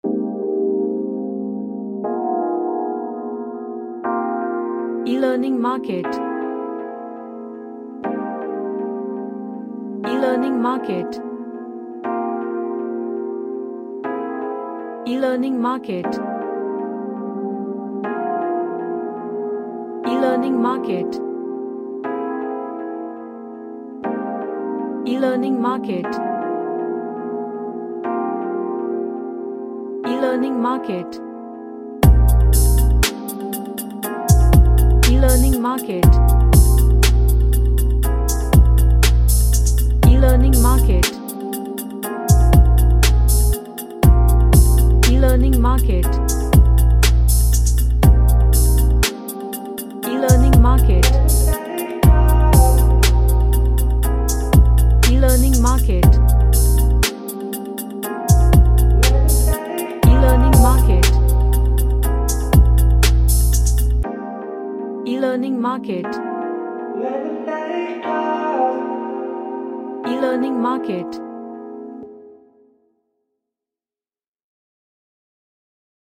An r&b chill track
Gentle / Light